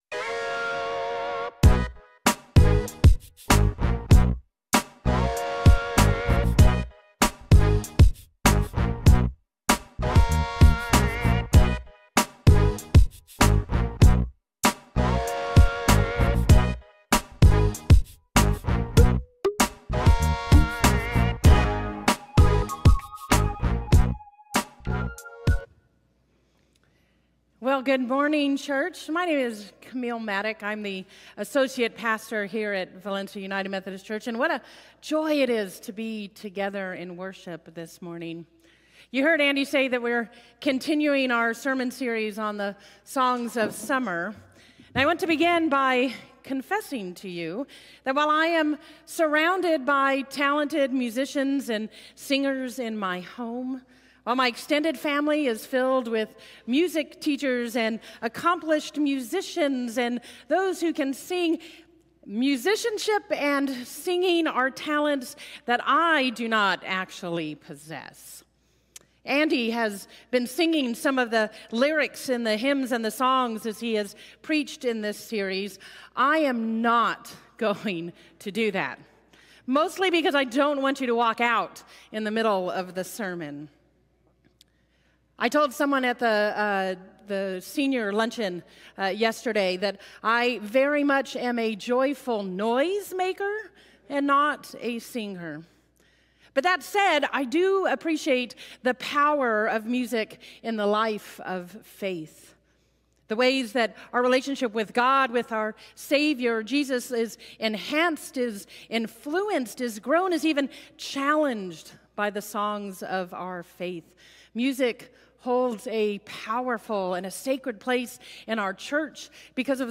Sermon 7_14 Audio.mp3